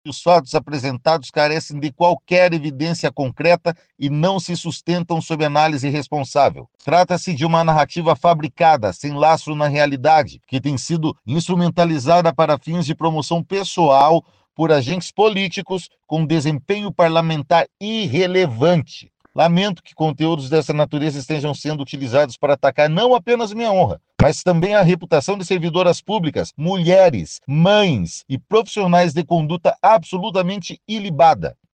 Eder Borges rebateu as acusações e disse que é alvo de vereadores da Oposição.